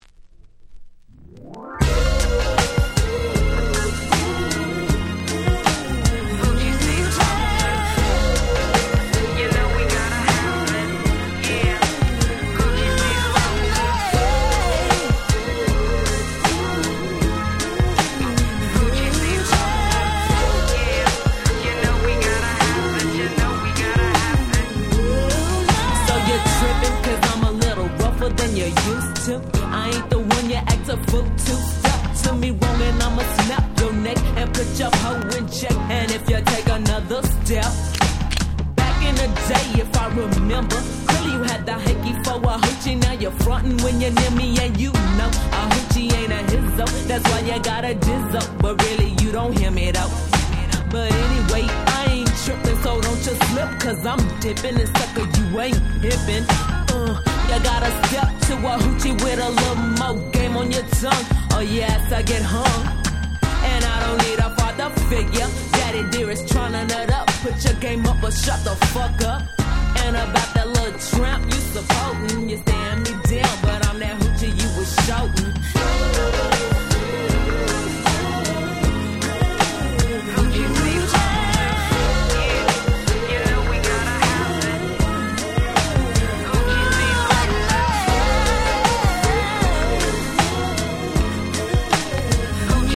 94' Smash Hit Hip Hop / G-Rap / Gangsta Rap !!